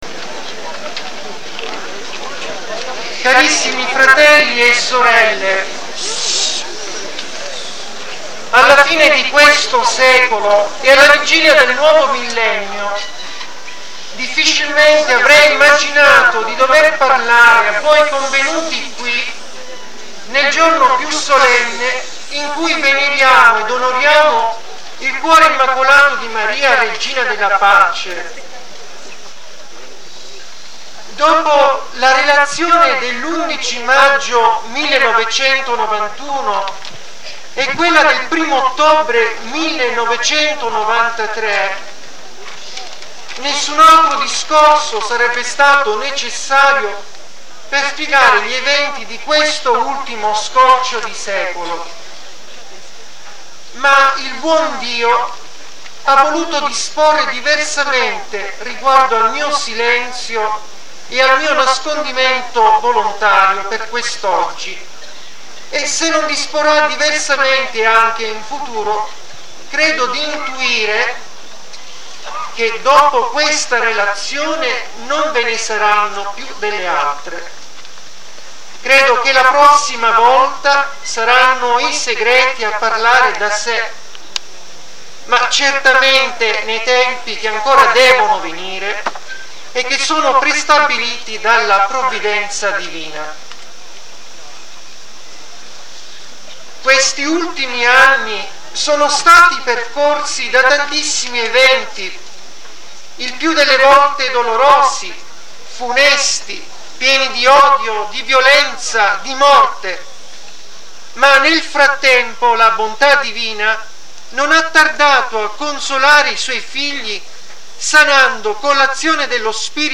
11 maggio 1999 (dal vivo - mp3)